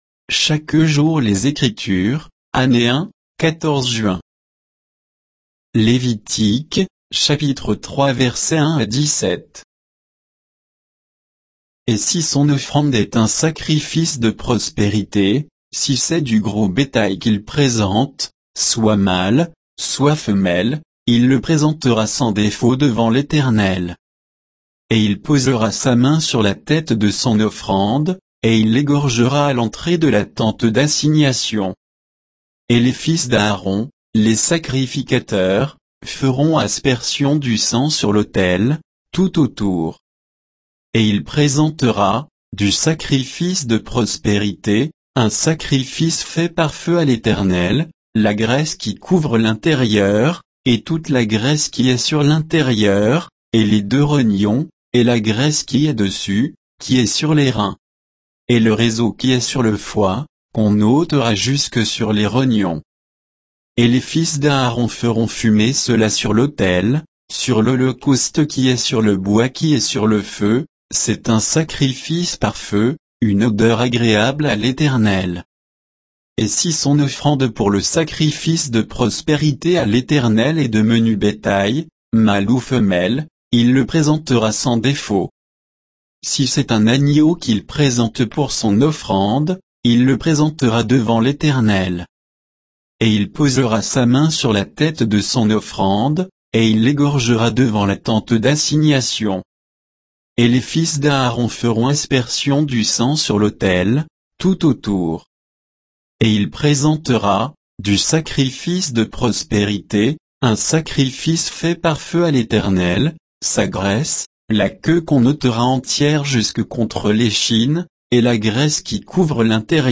Méditation quoditienne de Chaque jour les Écritures sur Lévitique 3, 1 à 17